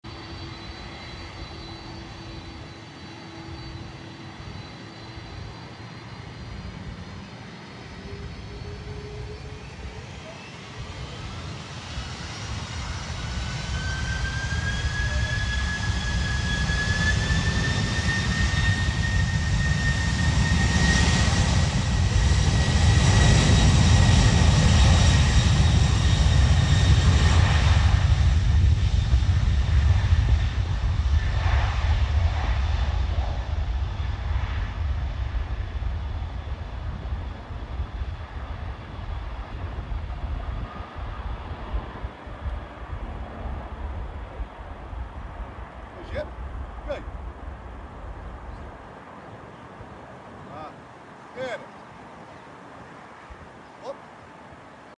BOEING 747-45EF - ApexLogistic Atlas